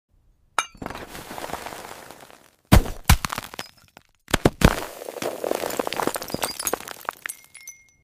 0–2s: Silence, faint frost vapor drifting. 2s: Steel press touches surface → low resonant “thunk”. 2–5s: Crystal rind fractures → layered, sharp “crrrrk-crk-crk” (glass-like). 5–7s: Interior bursts → glowing red gem-seeds scatter with high-pitched “plink-plink-plink” on ice. 7–8s: Seeds flicker faintly, with a soft “chime” as they settle.